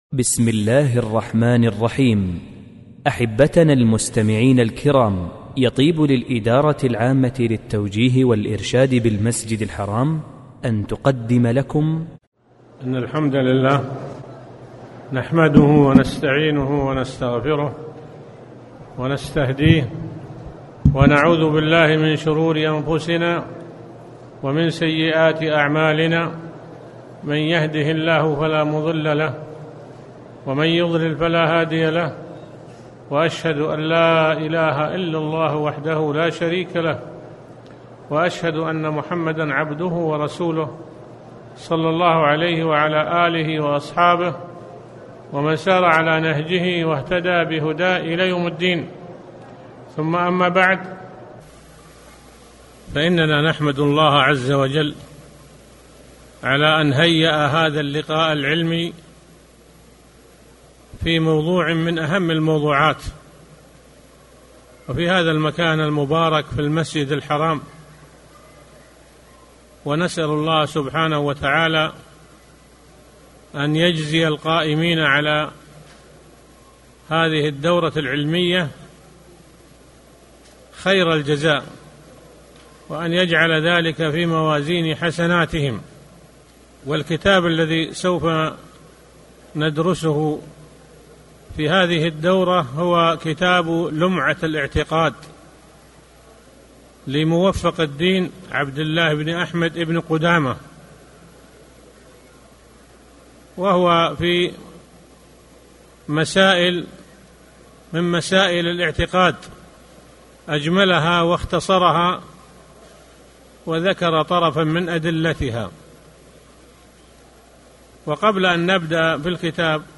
التعليق على كتاب "لمعة الاعتقاد"
أحبتنا المستمعين الكرام، يطيب للإدارة العامة للتوجيه والإرشاد بالمسجد الحرام أن تقدم لكم: